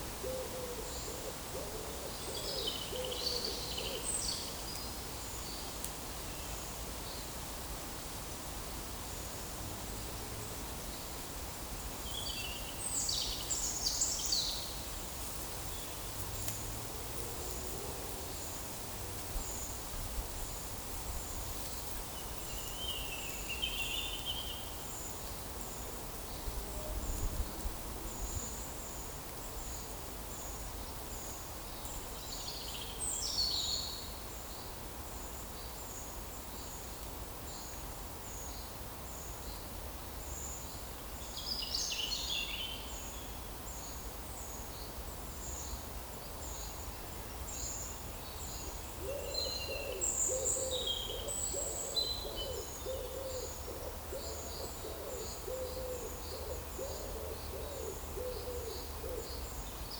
PEPR FORESTT - Monitor PAM - Renecofor
Columba palumbus
Certhia familiaris
Certhia brachydactyla
Turdus iliacus
Erithacus rubecula